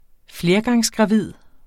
Udtale [ ˈfleɐ̯gɑŋsgʁɑˌviðˀ ]